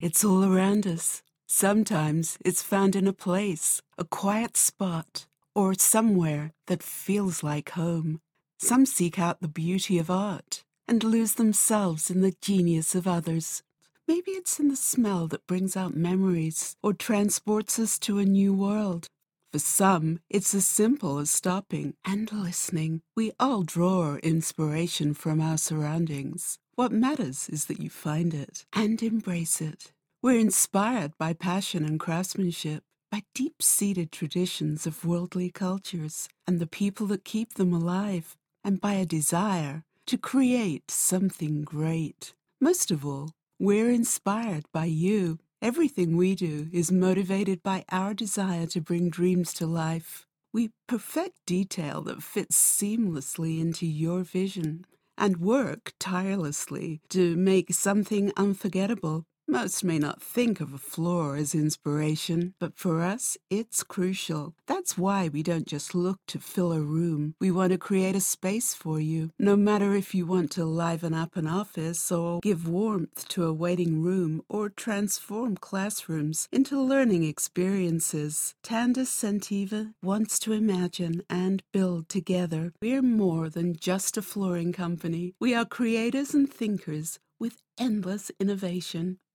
Voice Artist living in Los Angeles working in British and Global Transatlantic-Mid-Atlantic English
Sprechprobe: Sonstiges (Muttersprache):